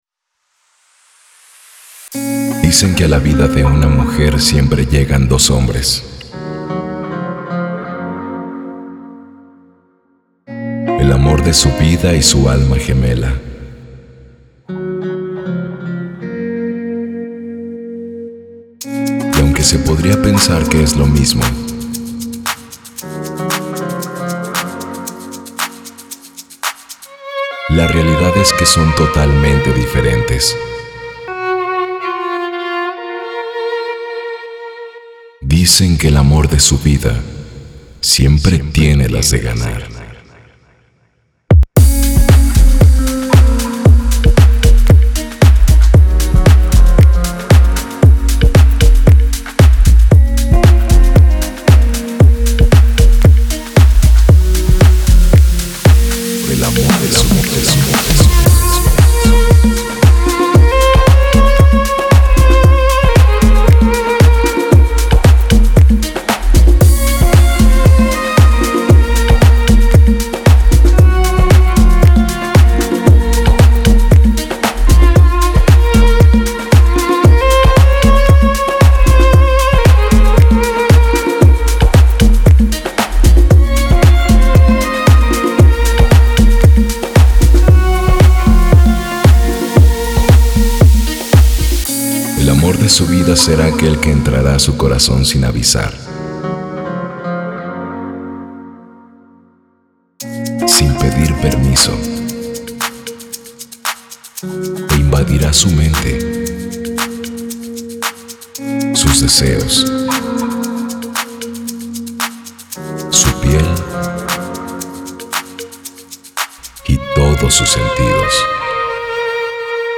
это эмоциональная композиция в жанре латинской поп-музыки